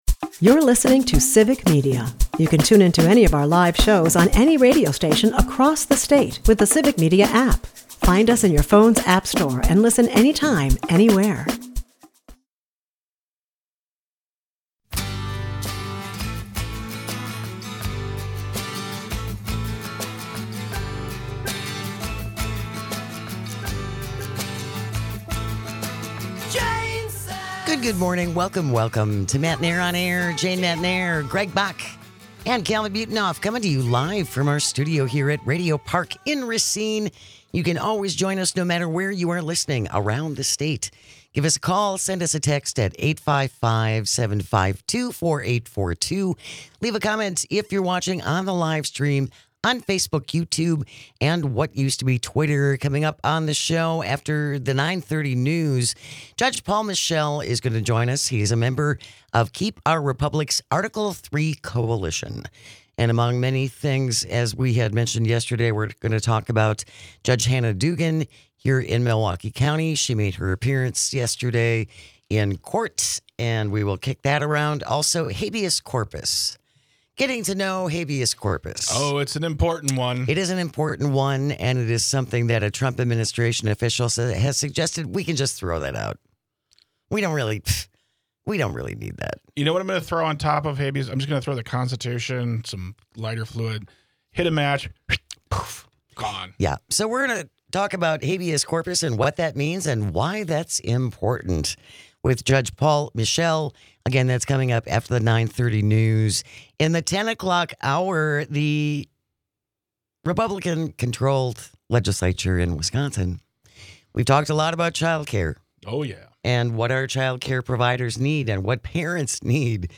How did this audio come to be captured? Matenaer On Air is a part of the Civic Media radio network and airs Monday through Friday from 9 -11 am across the state.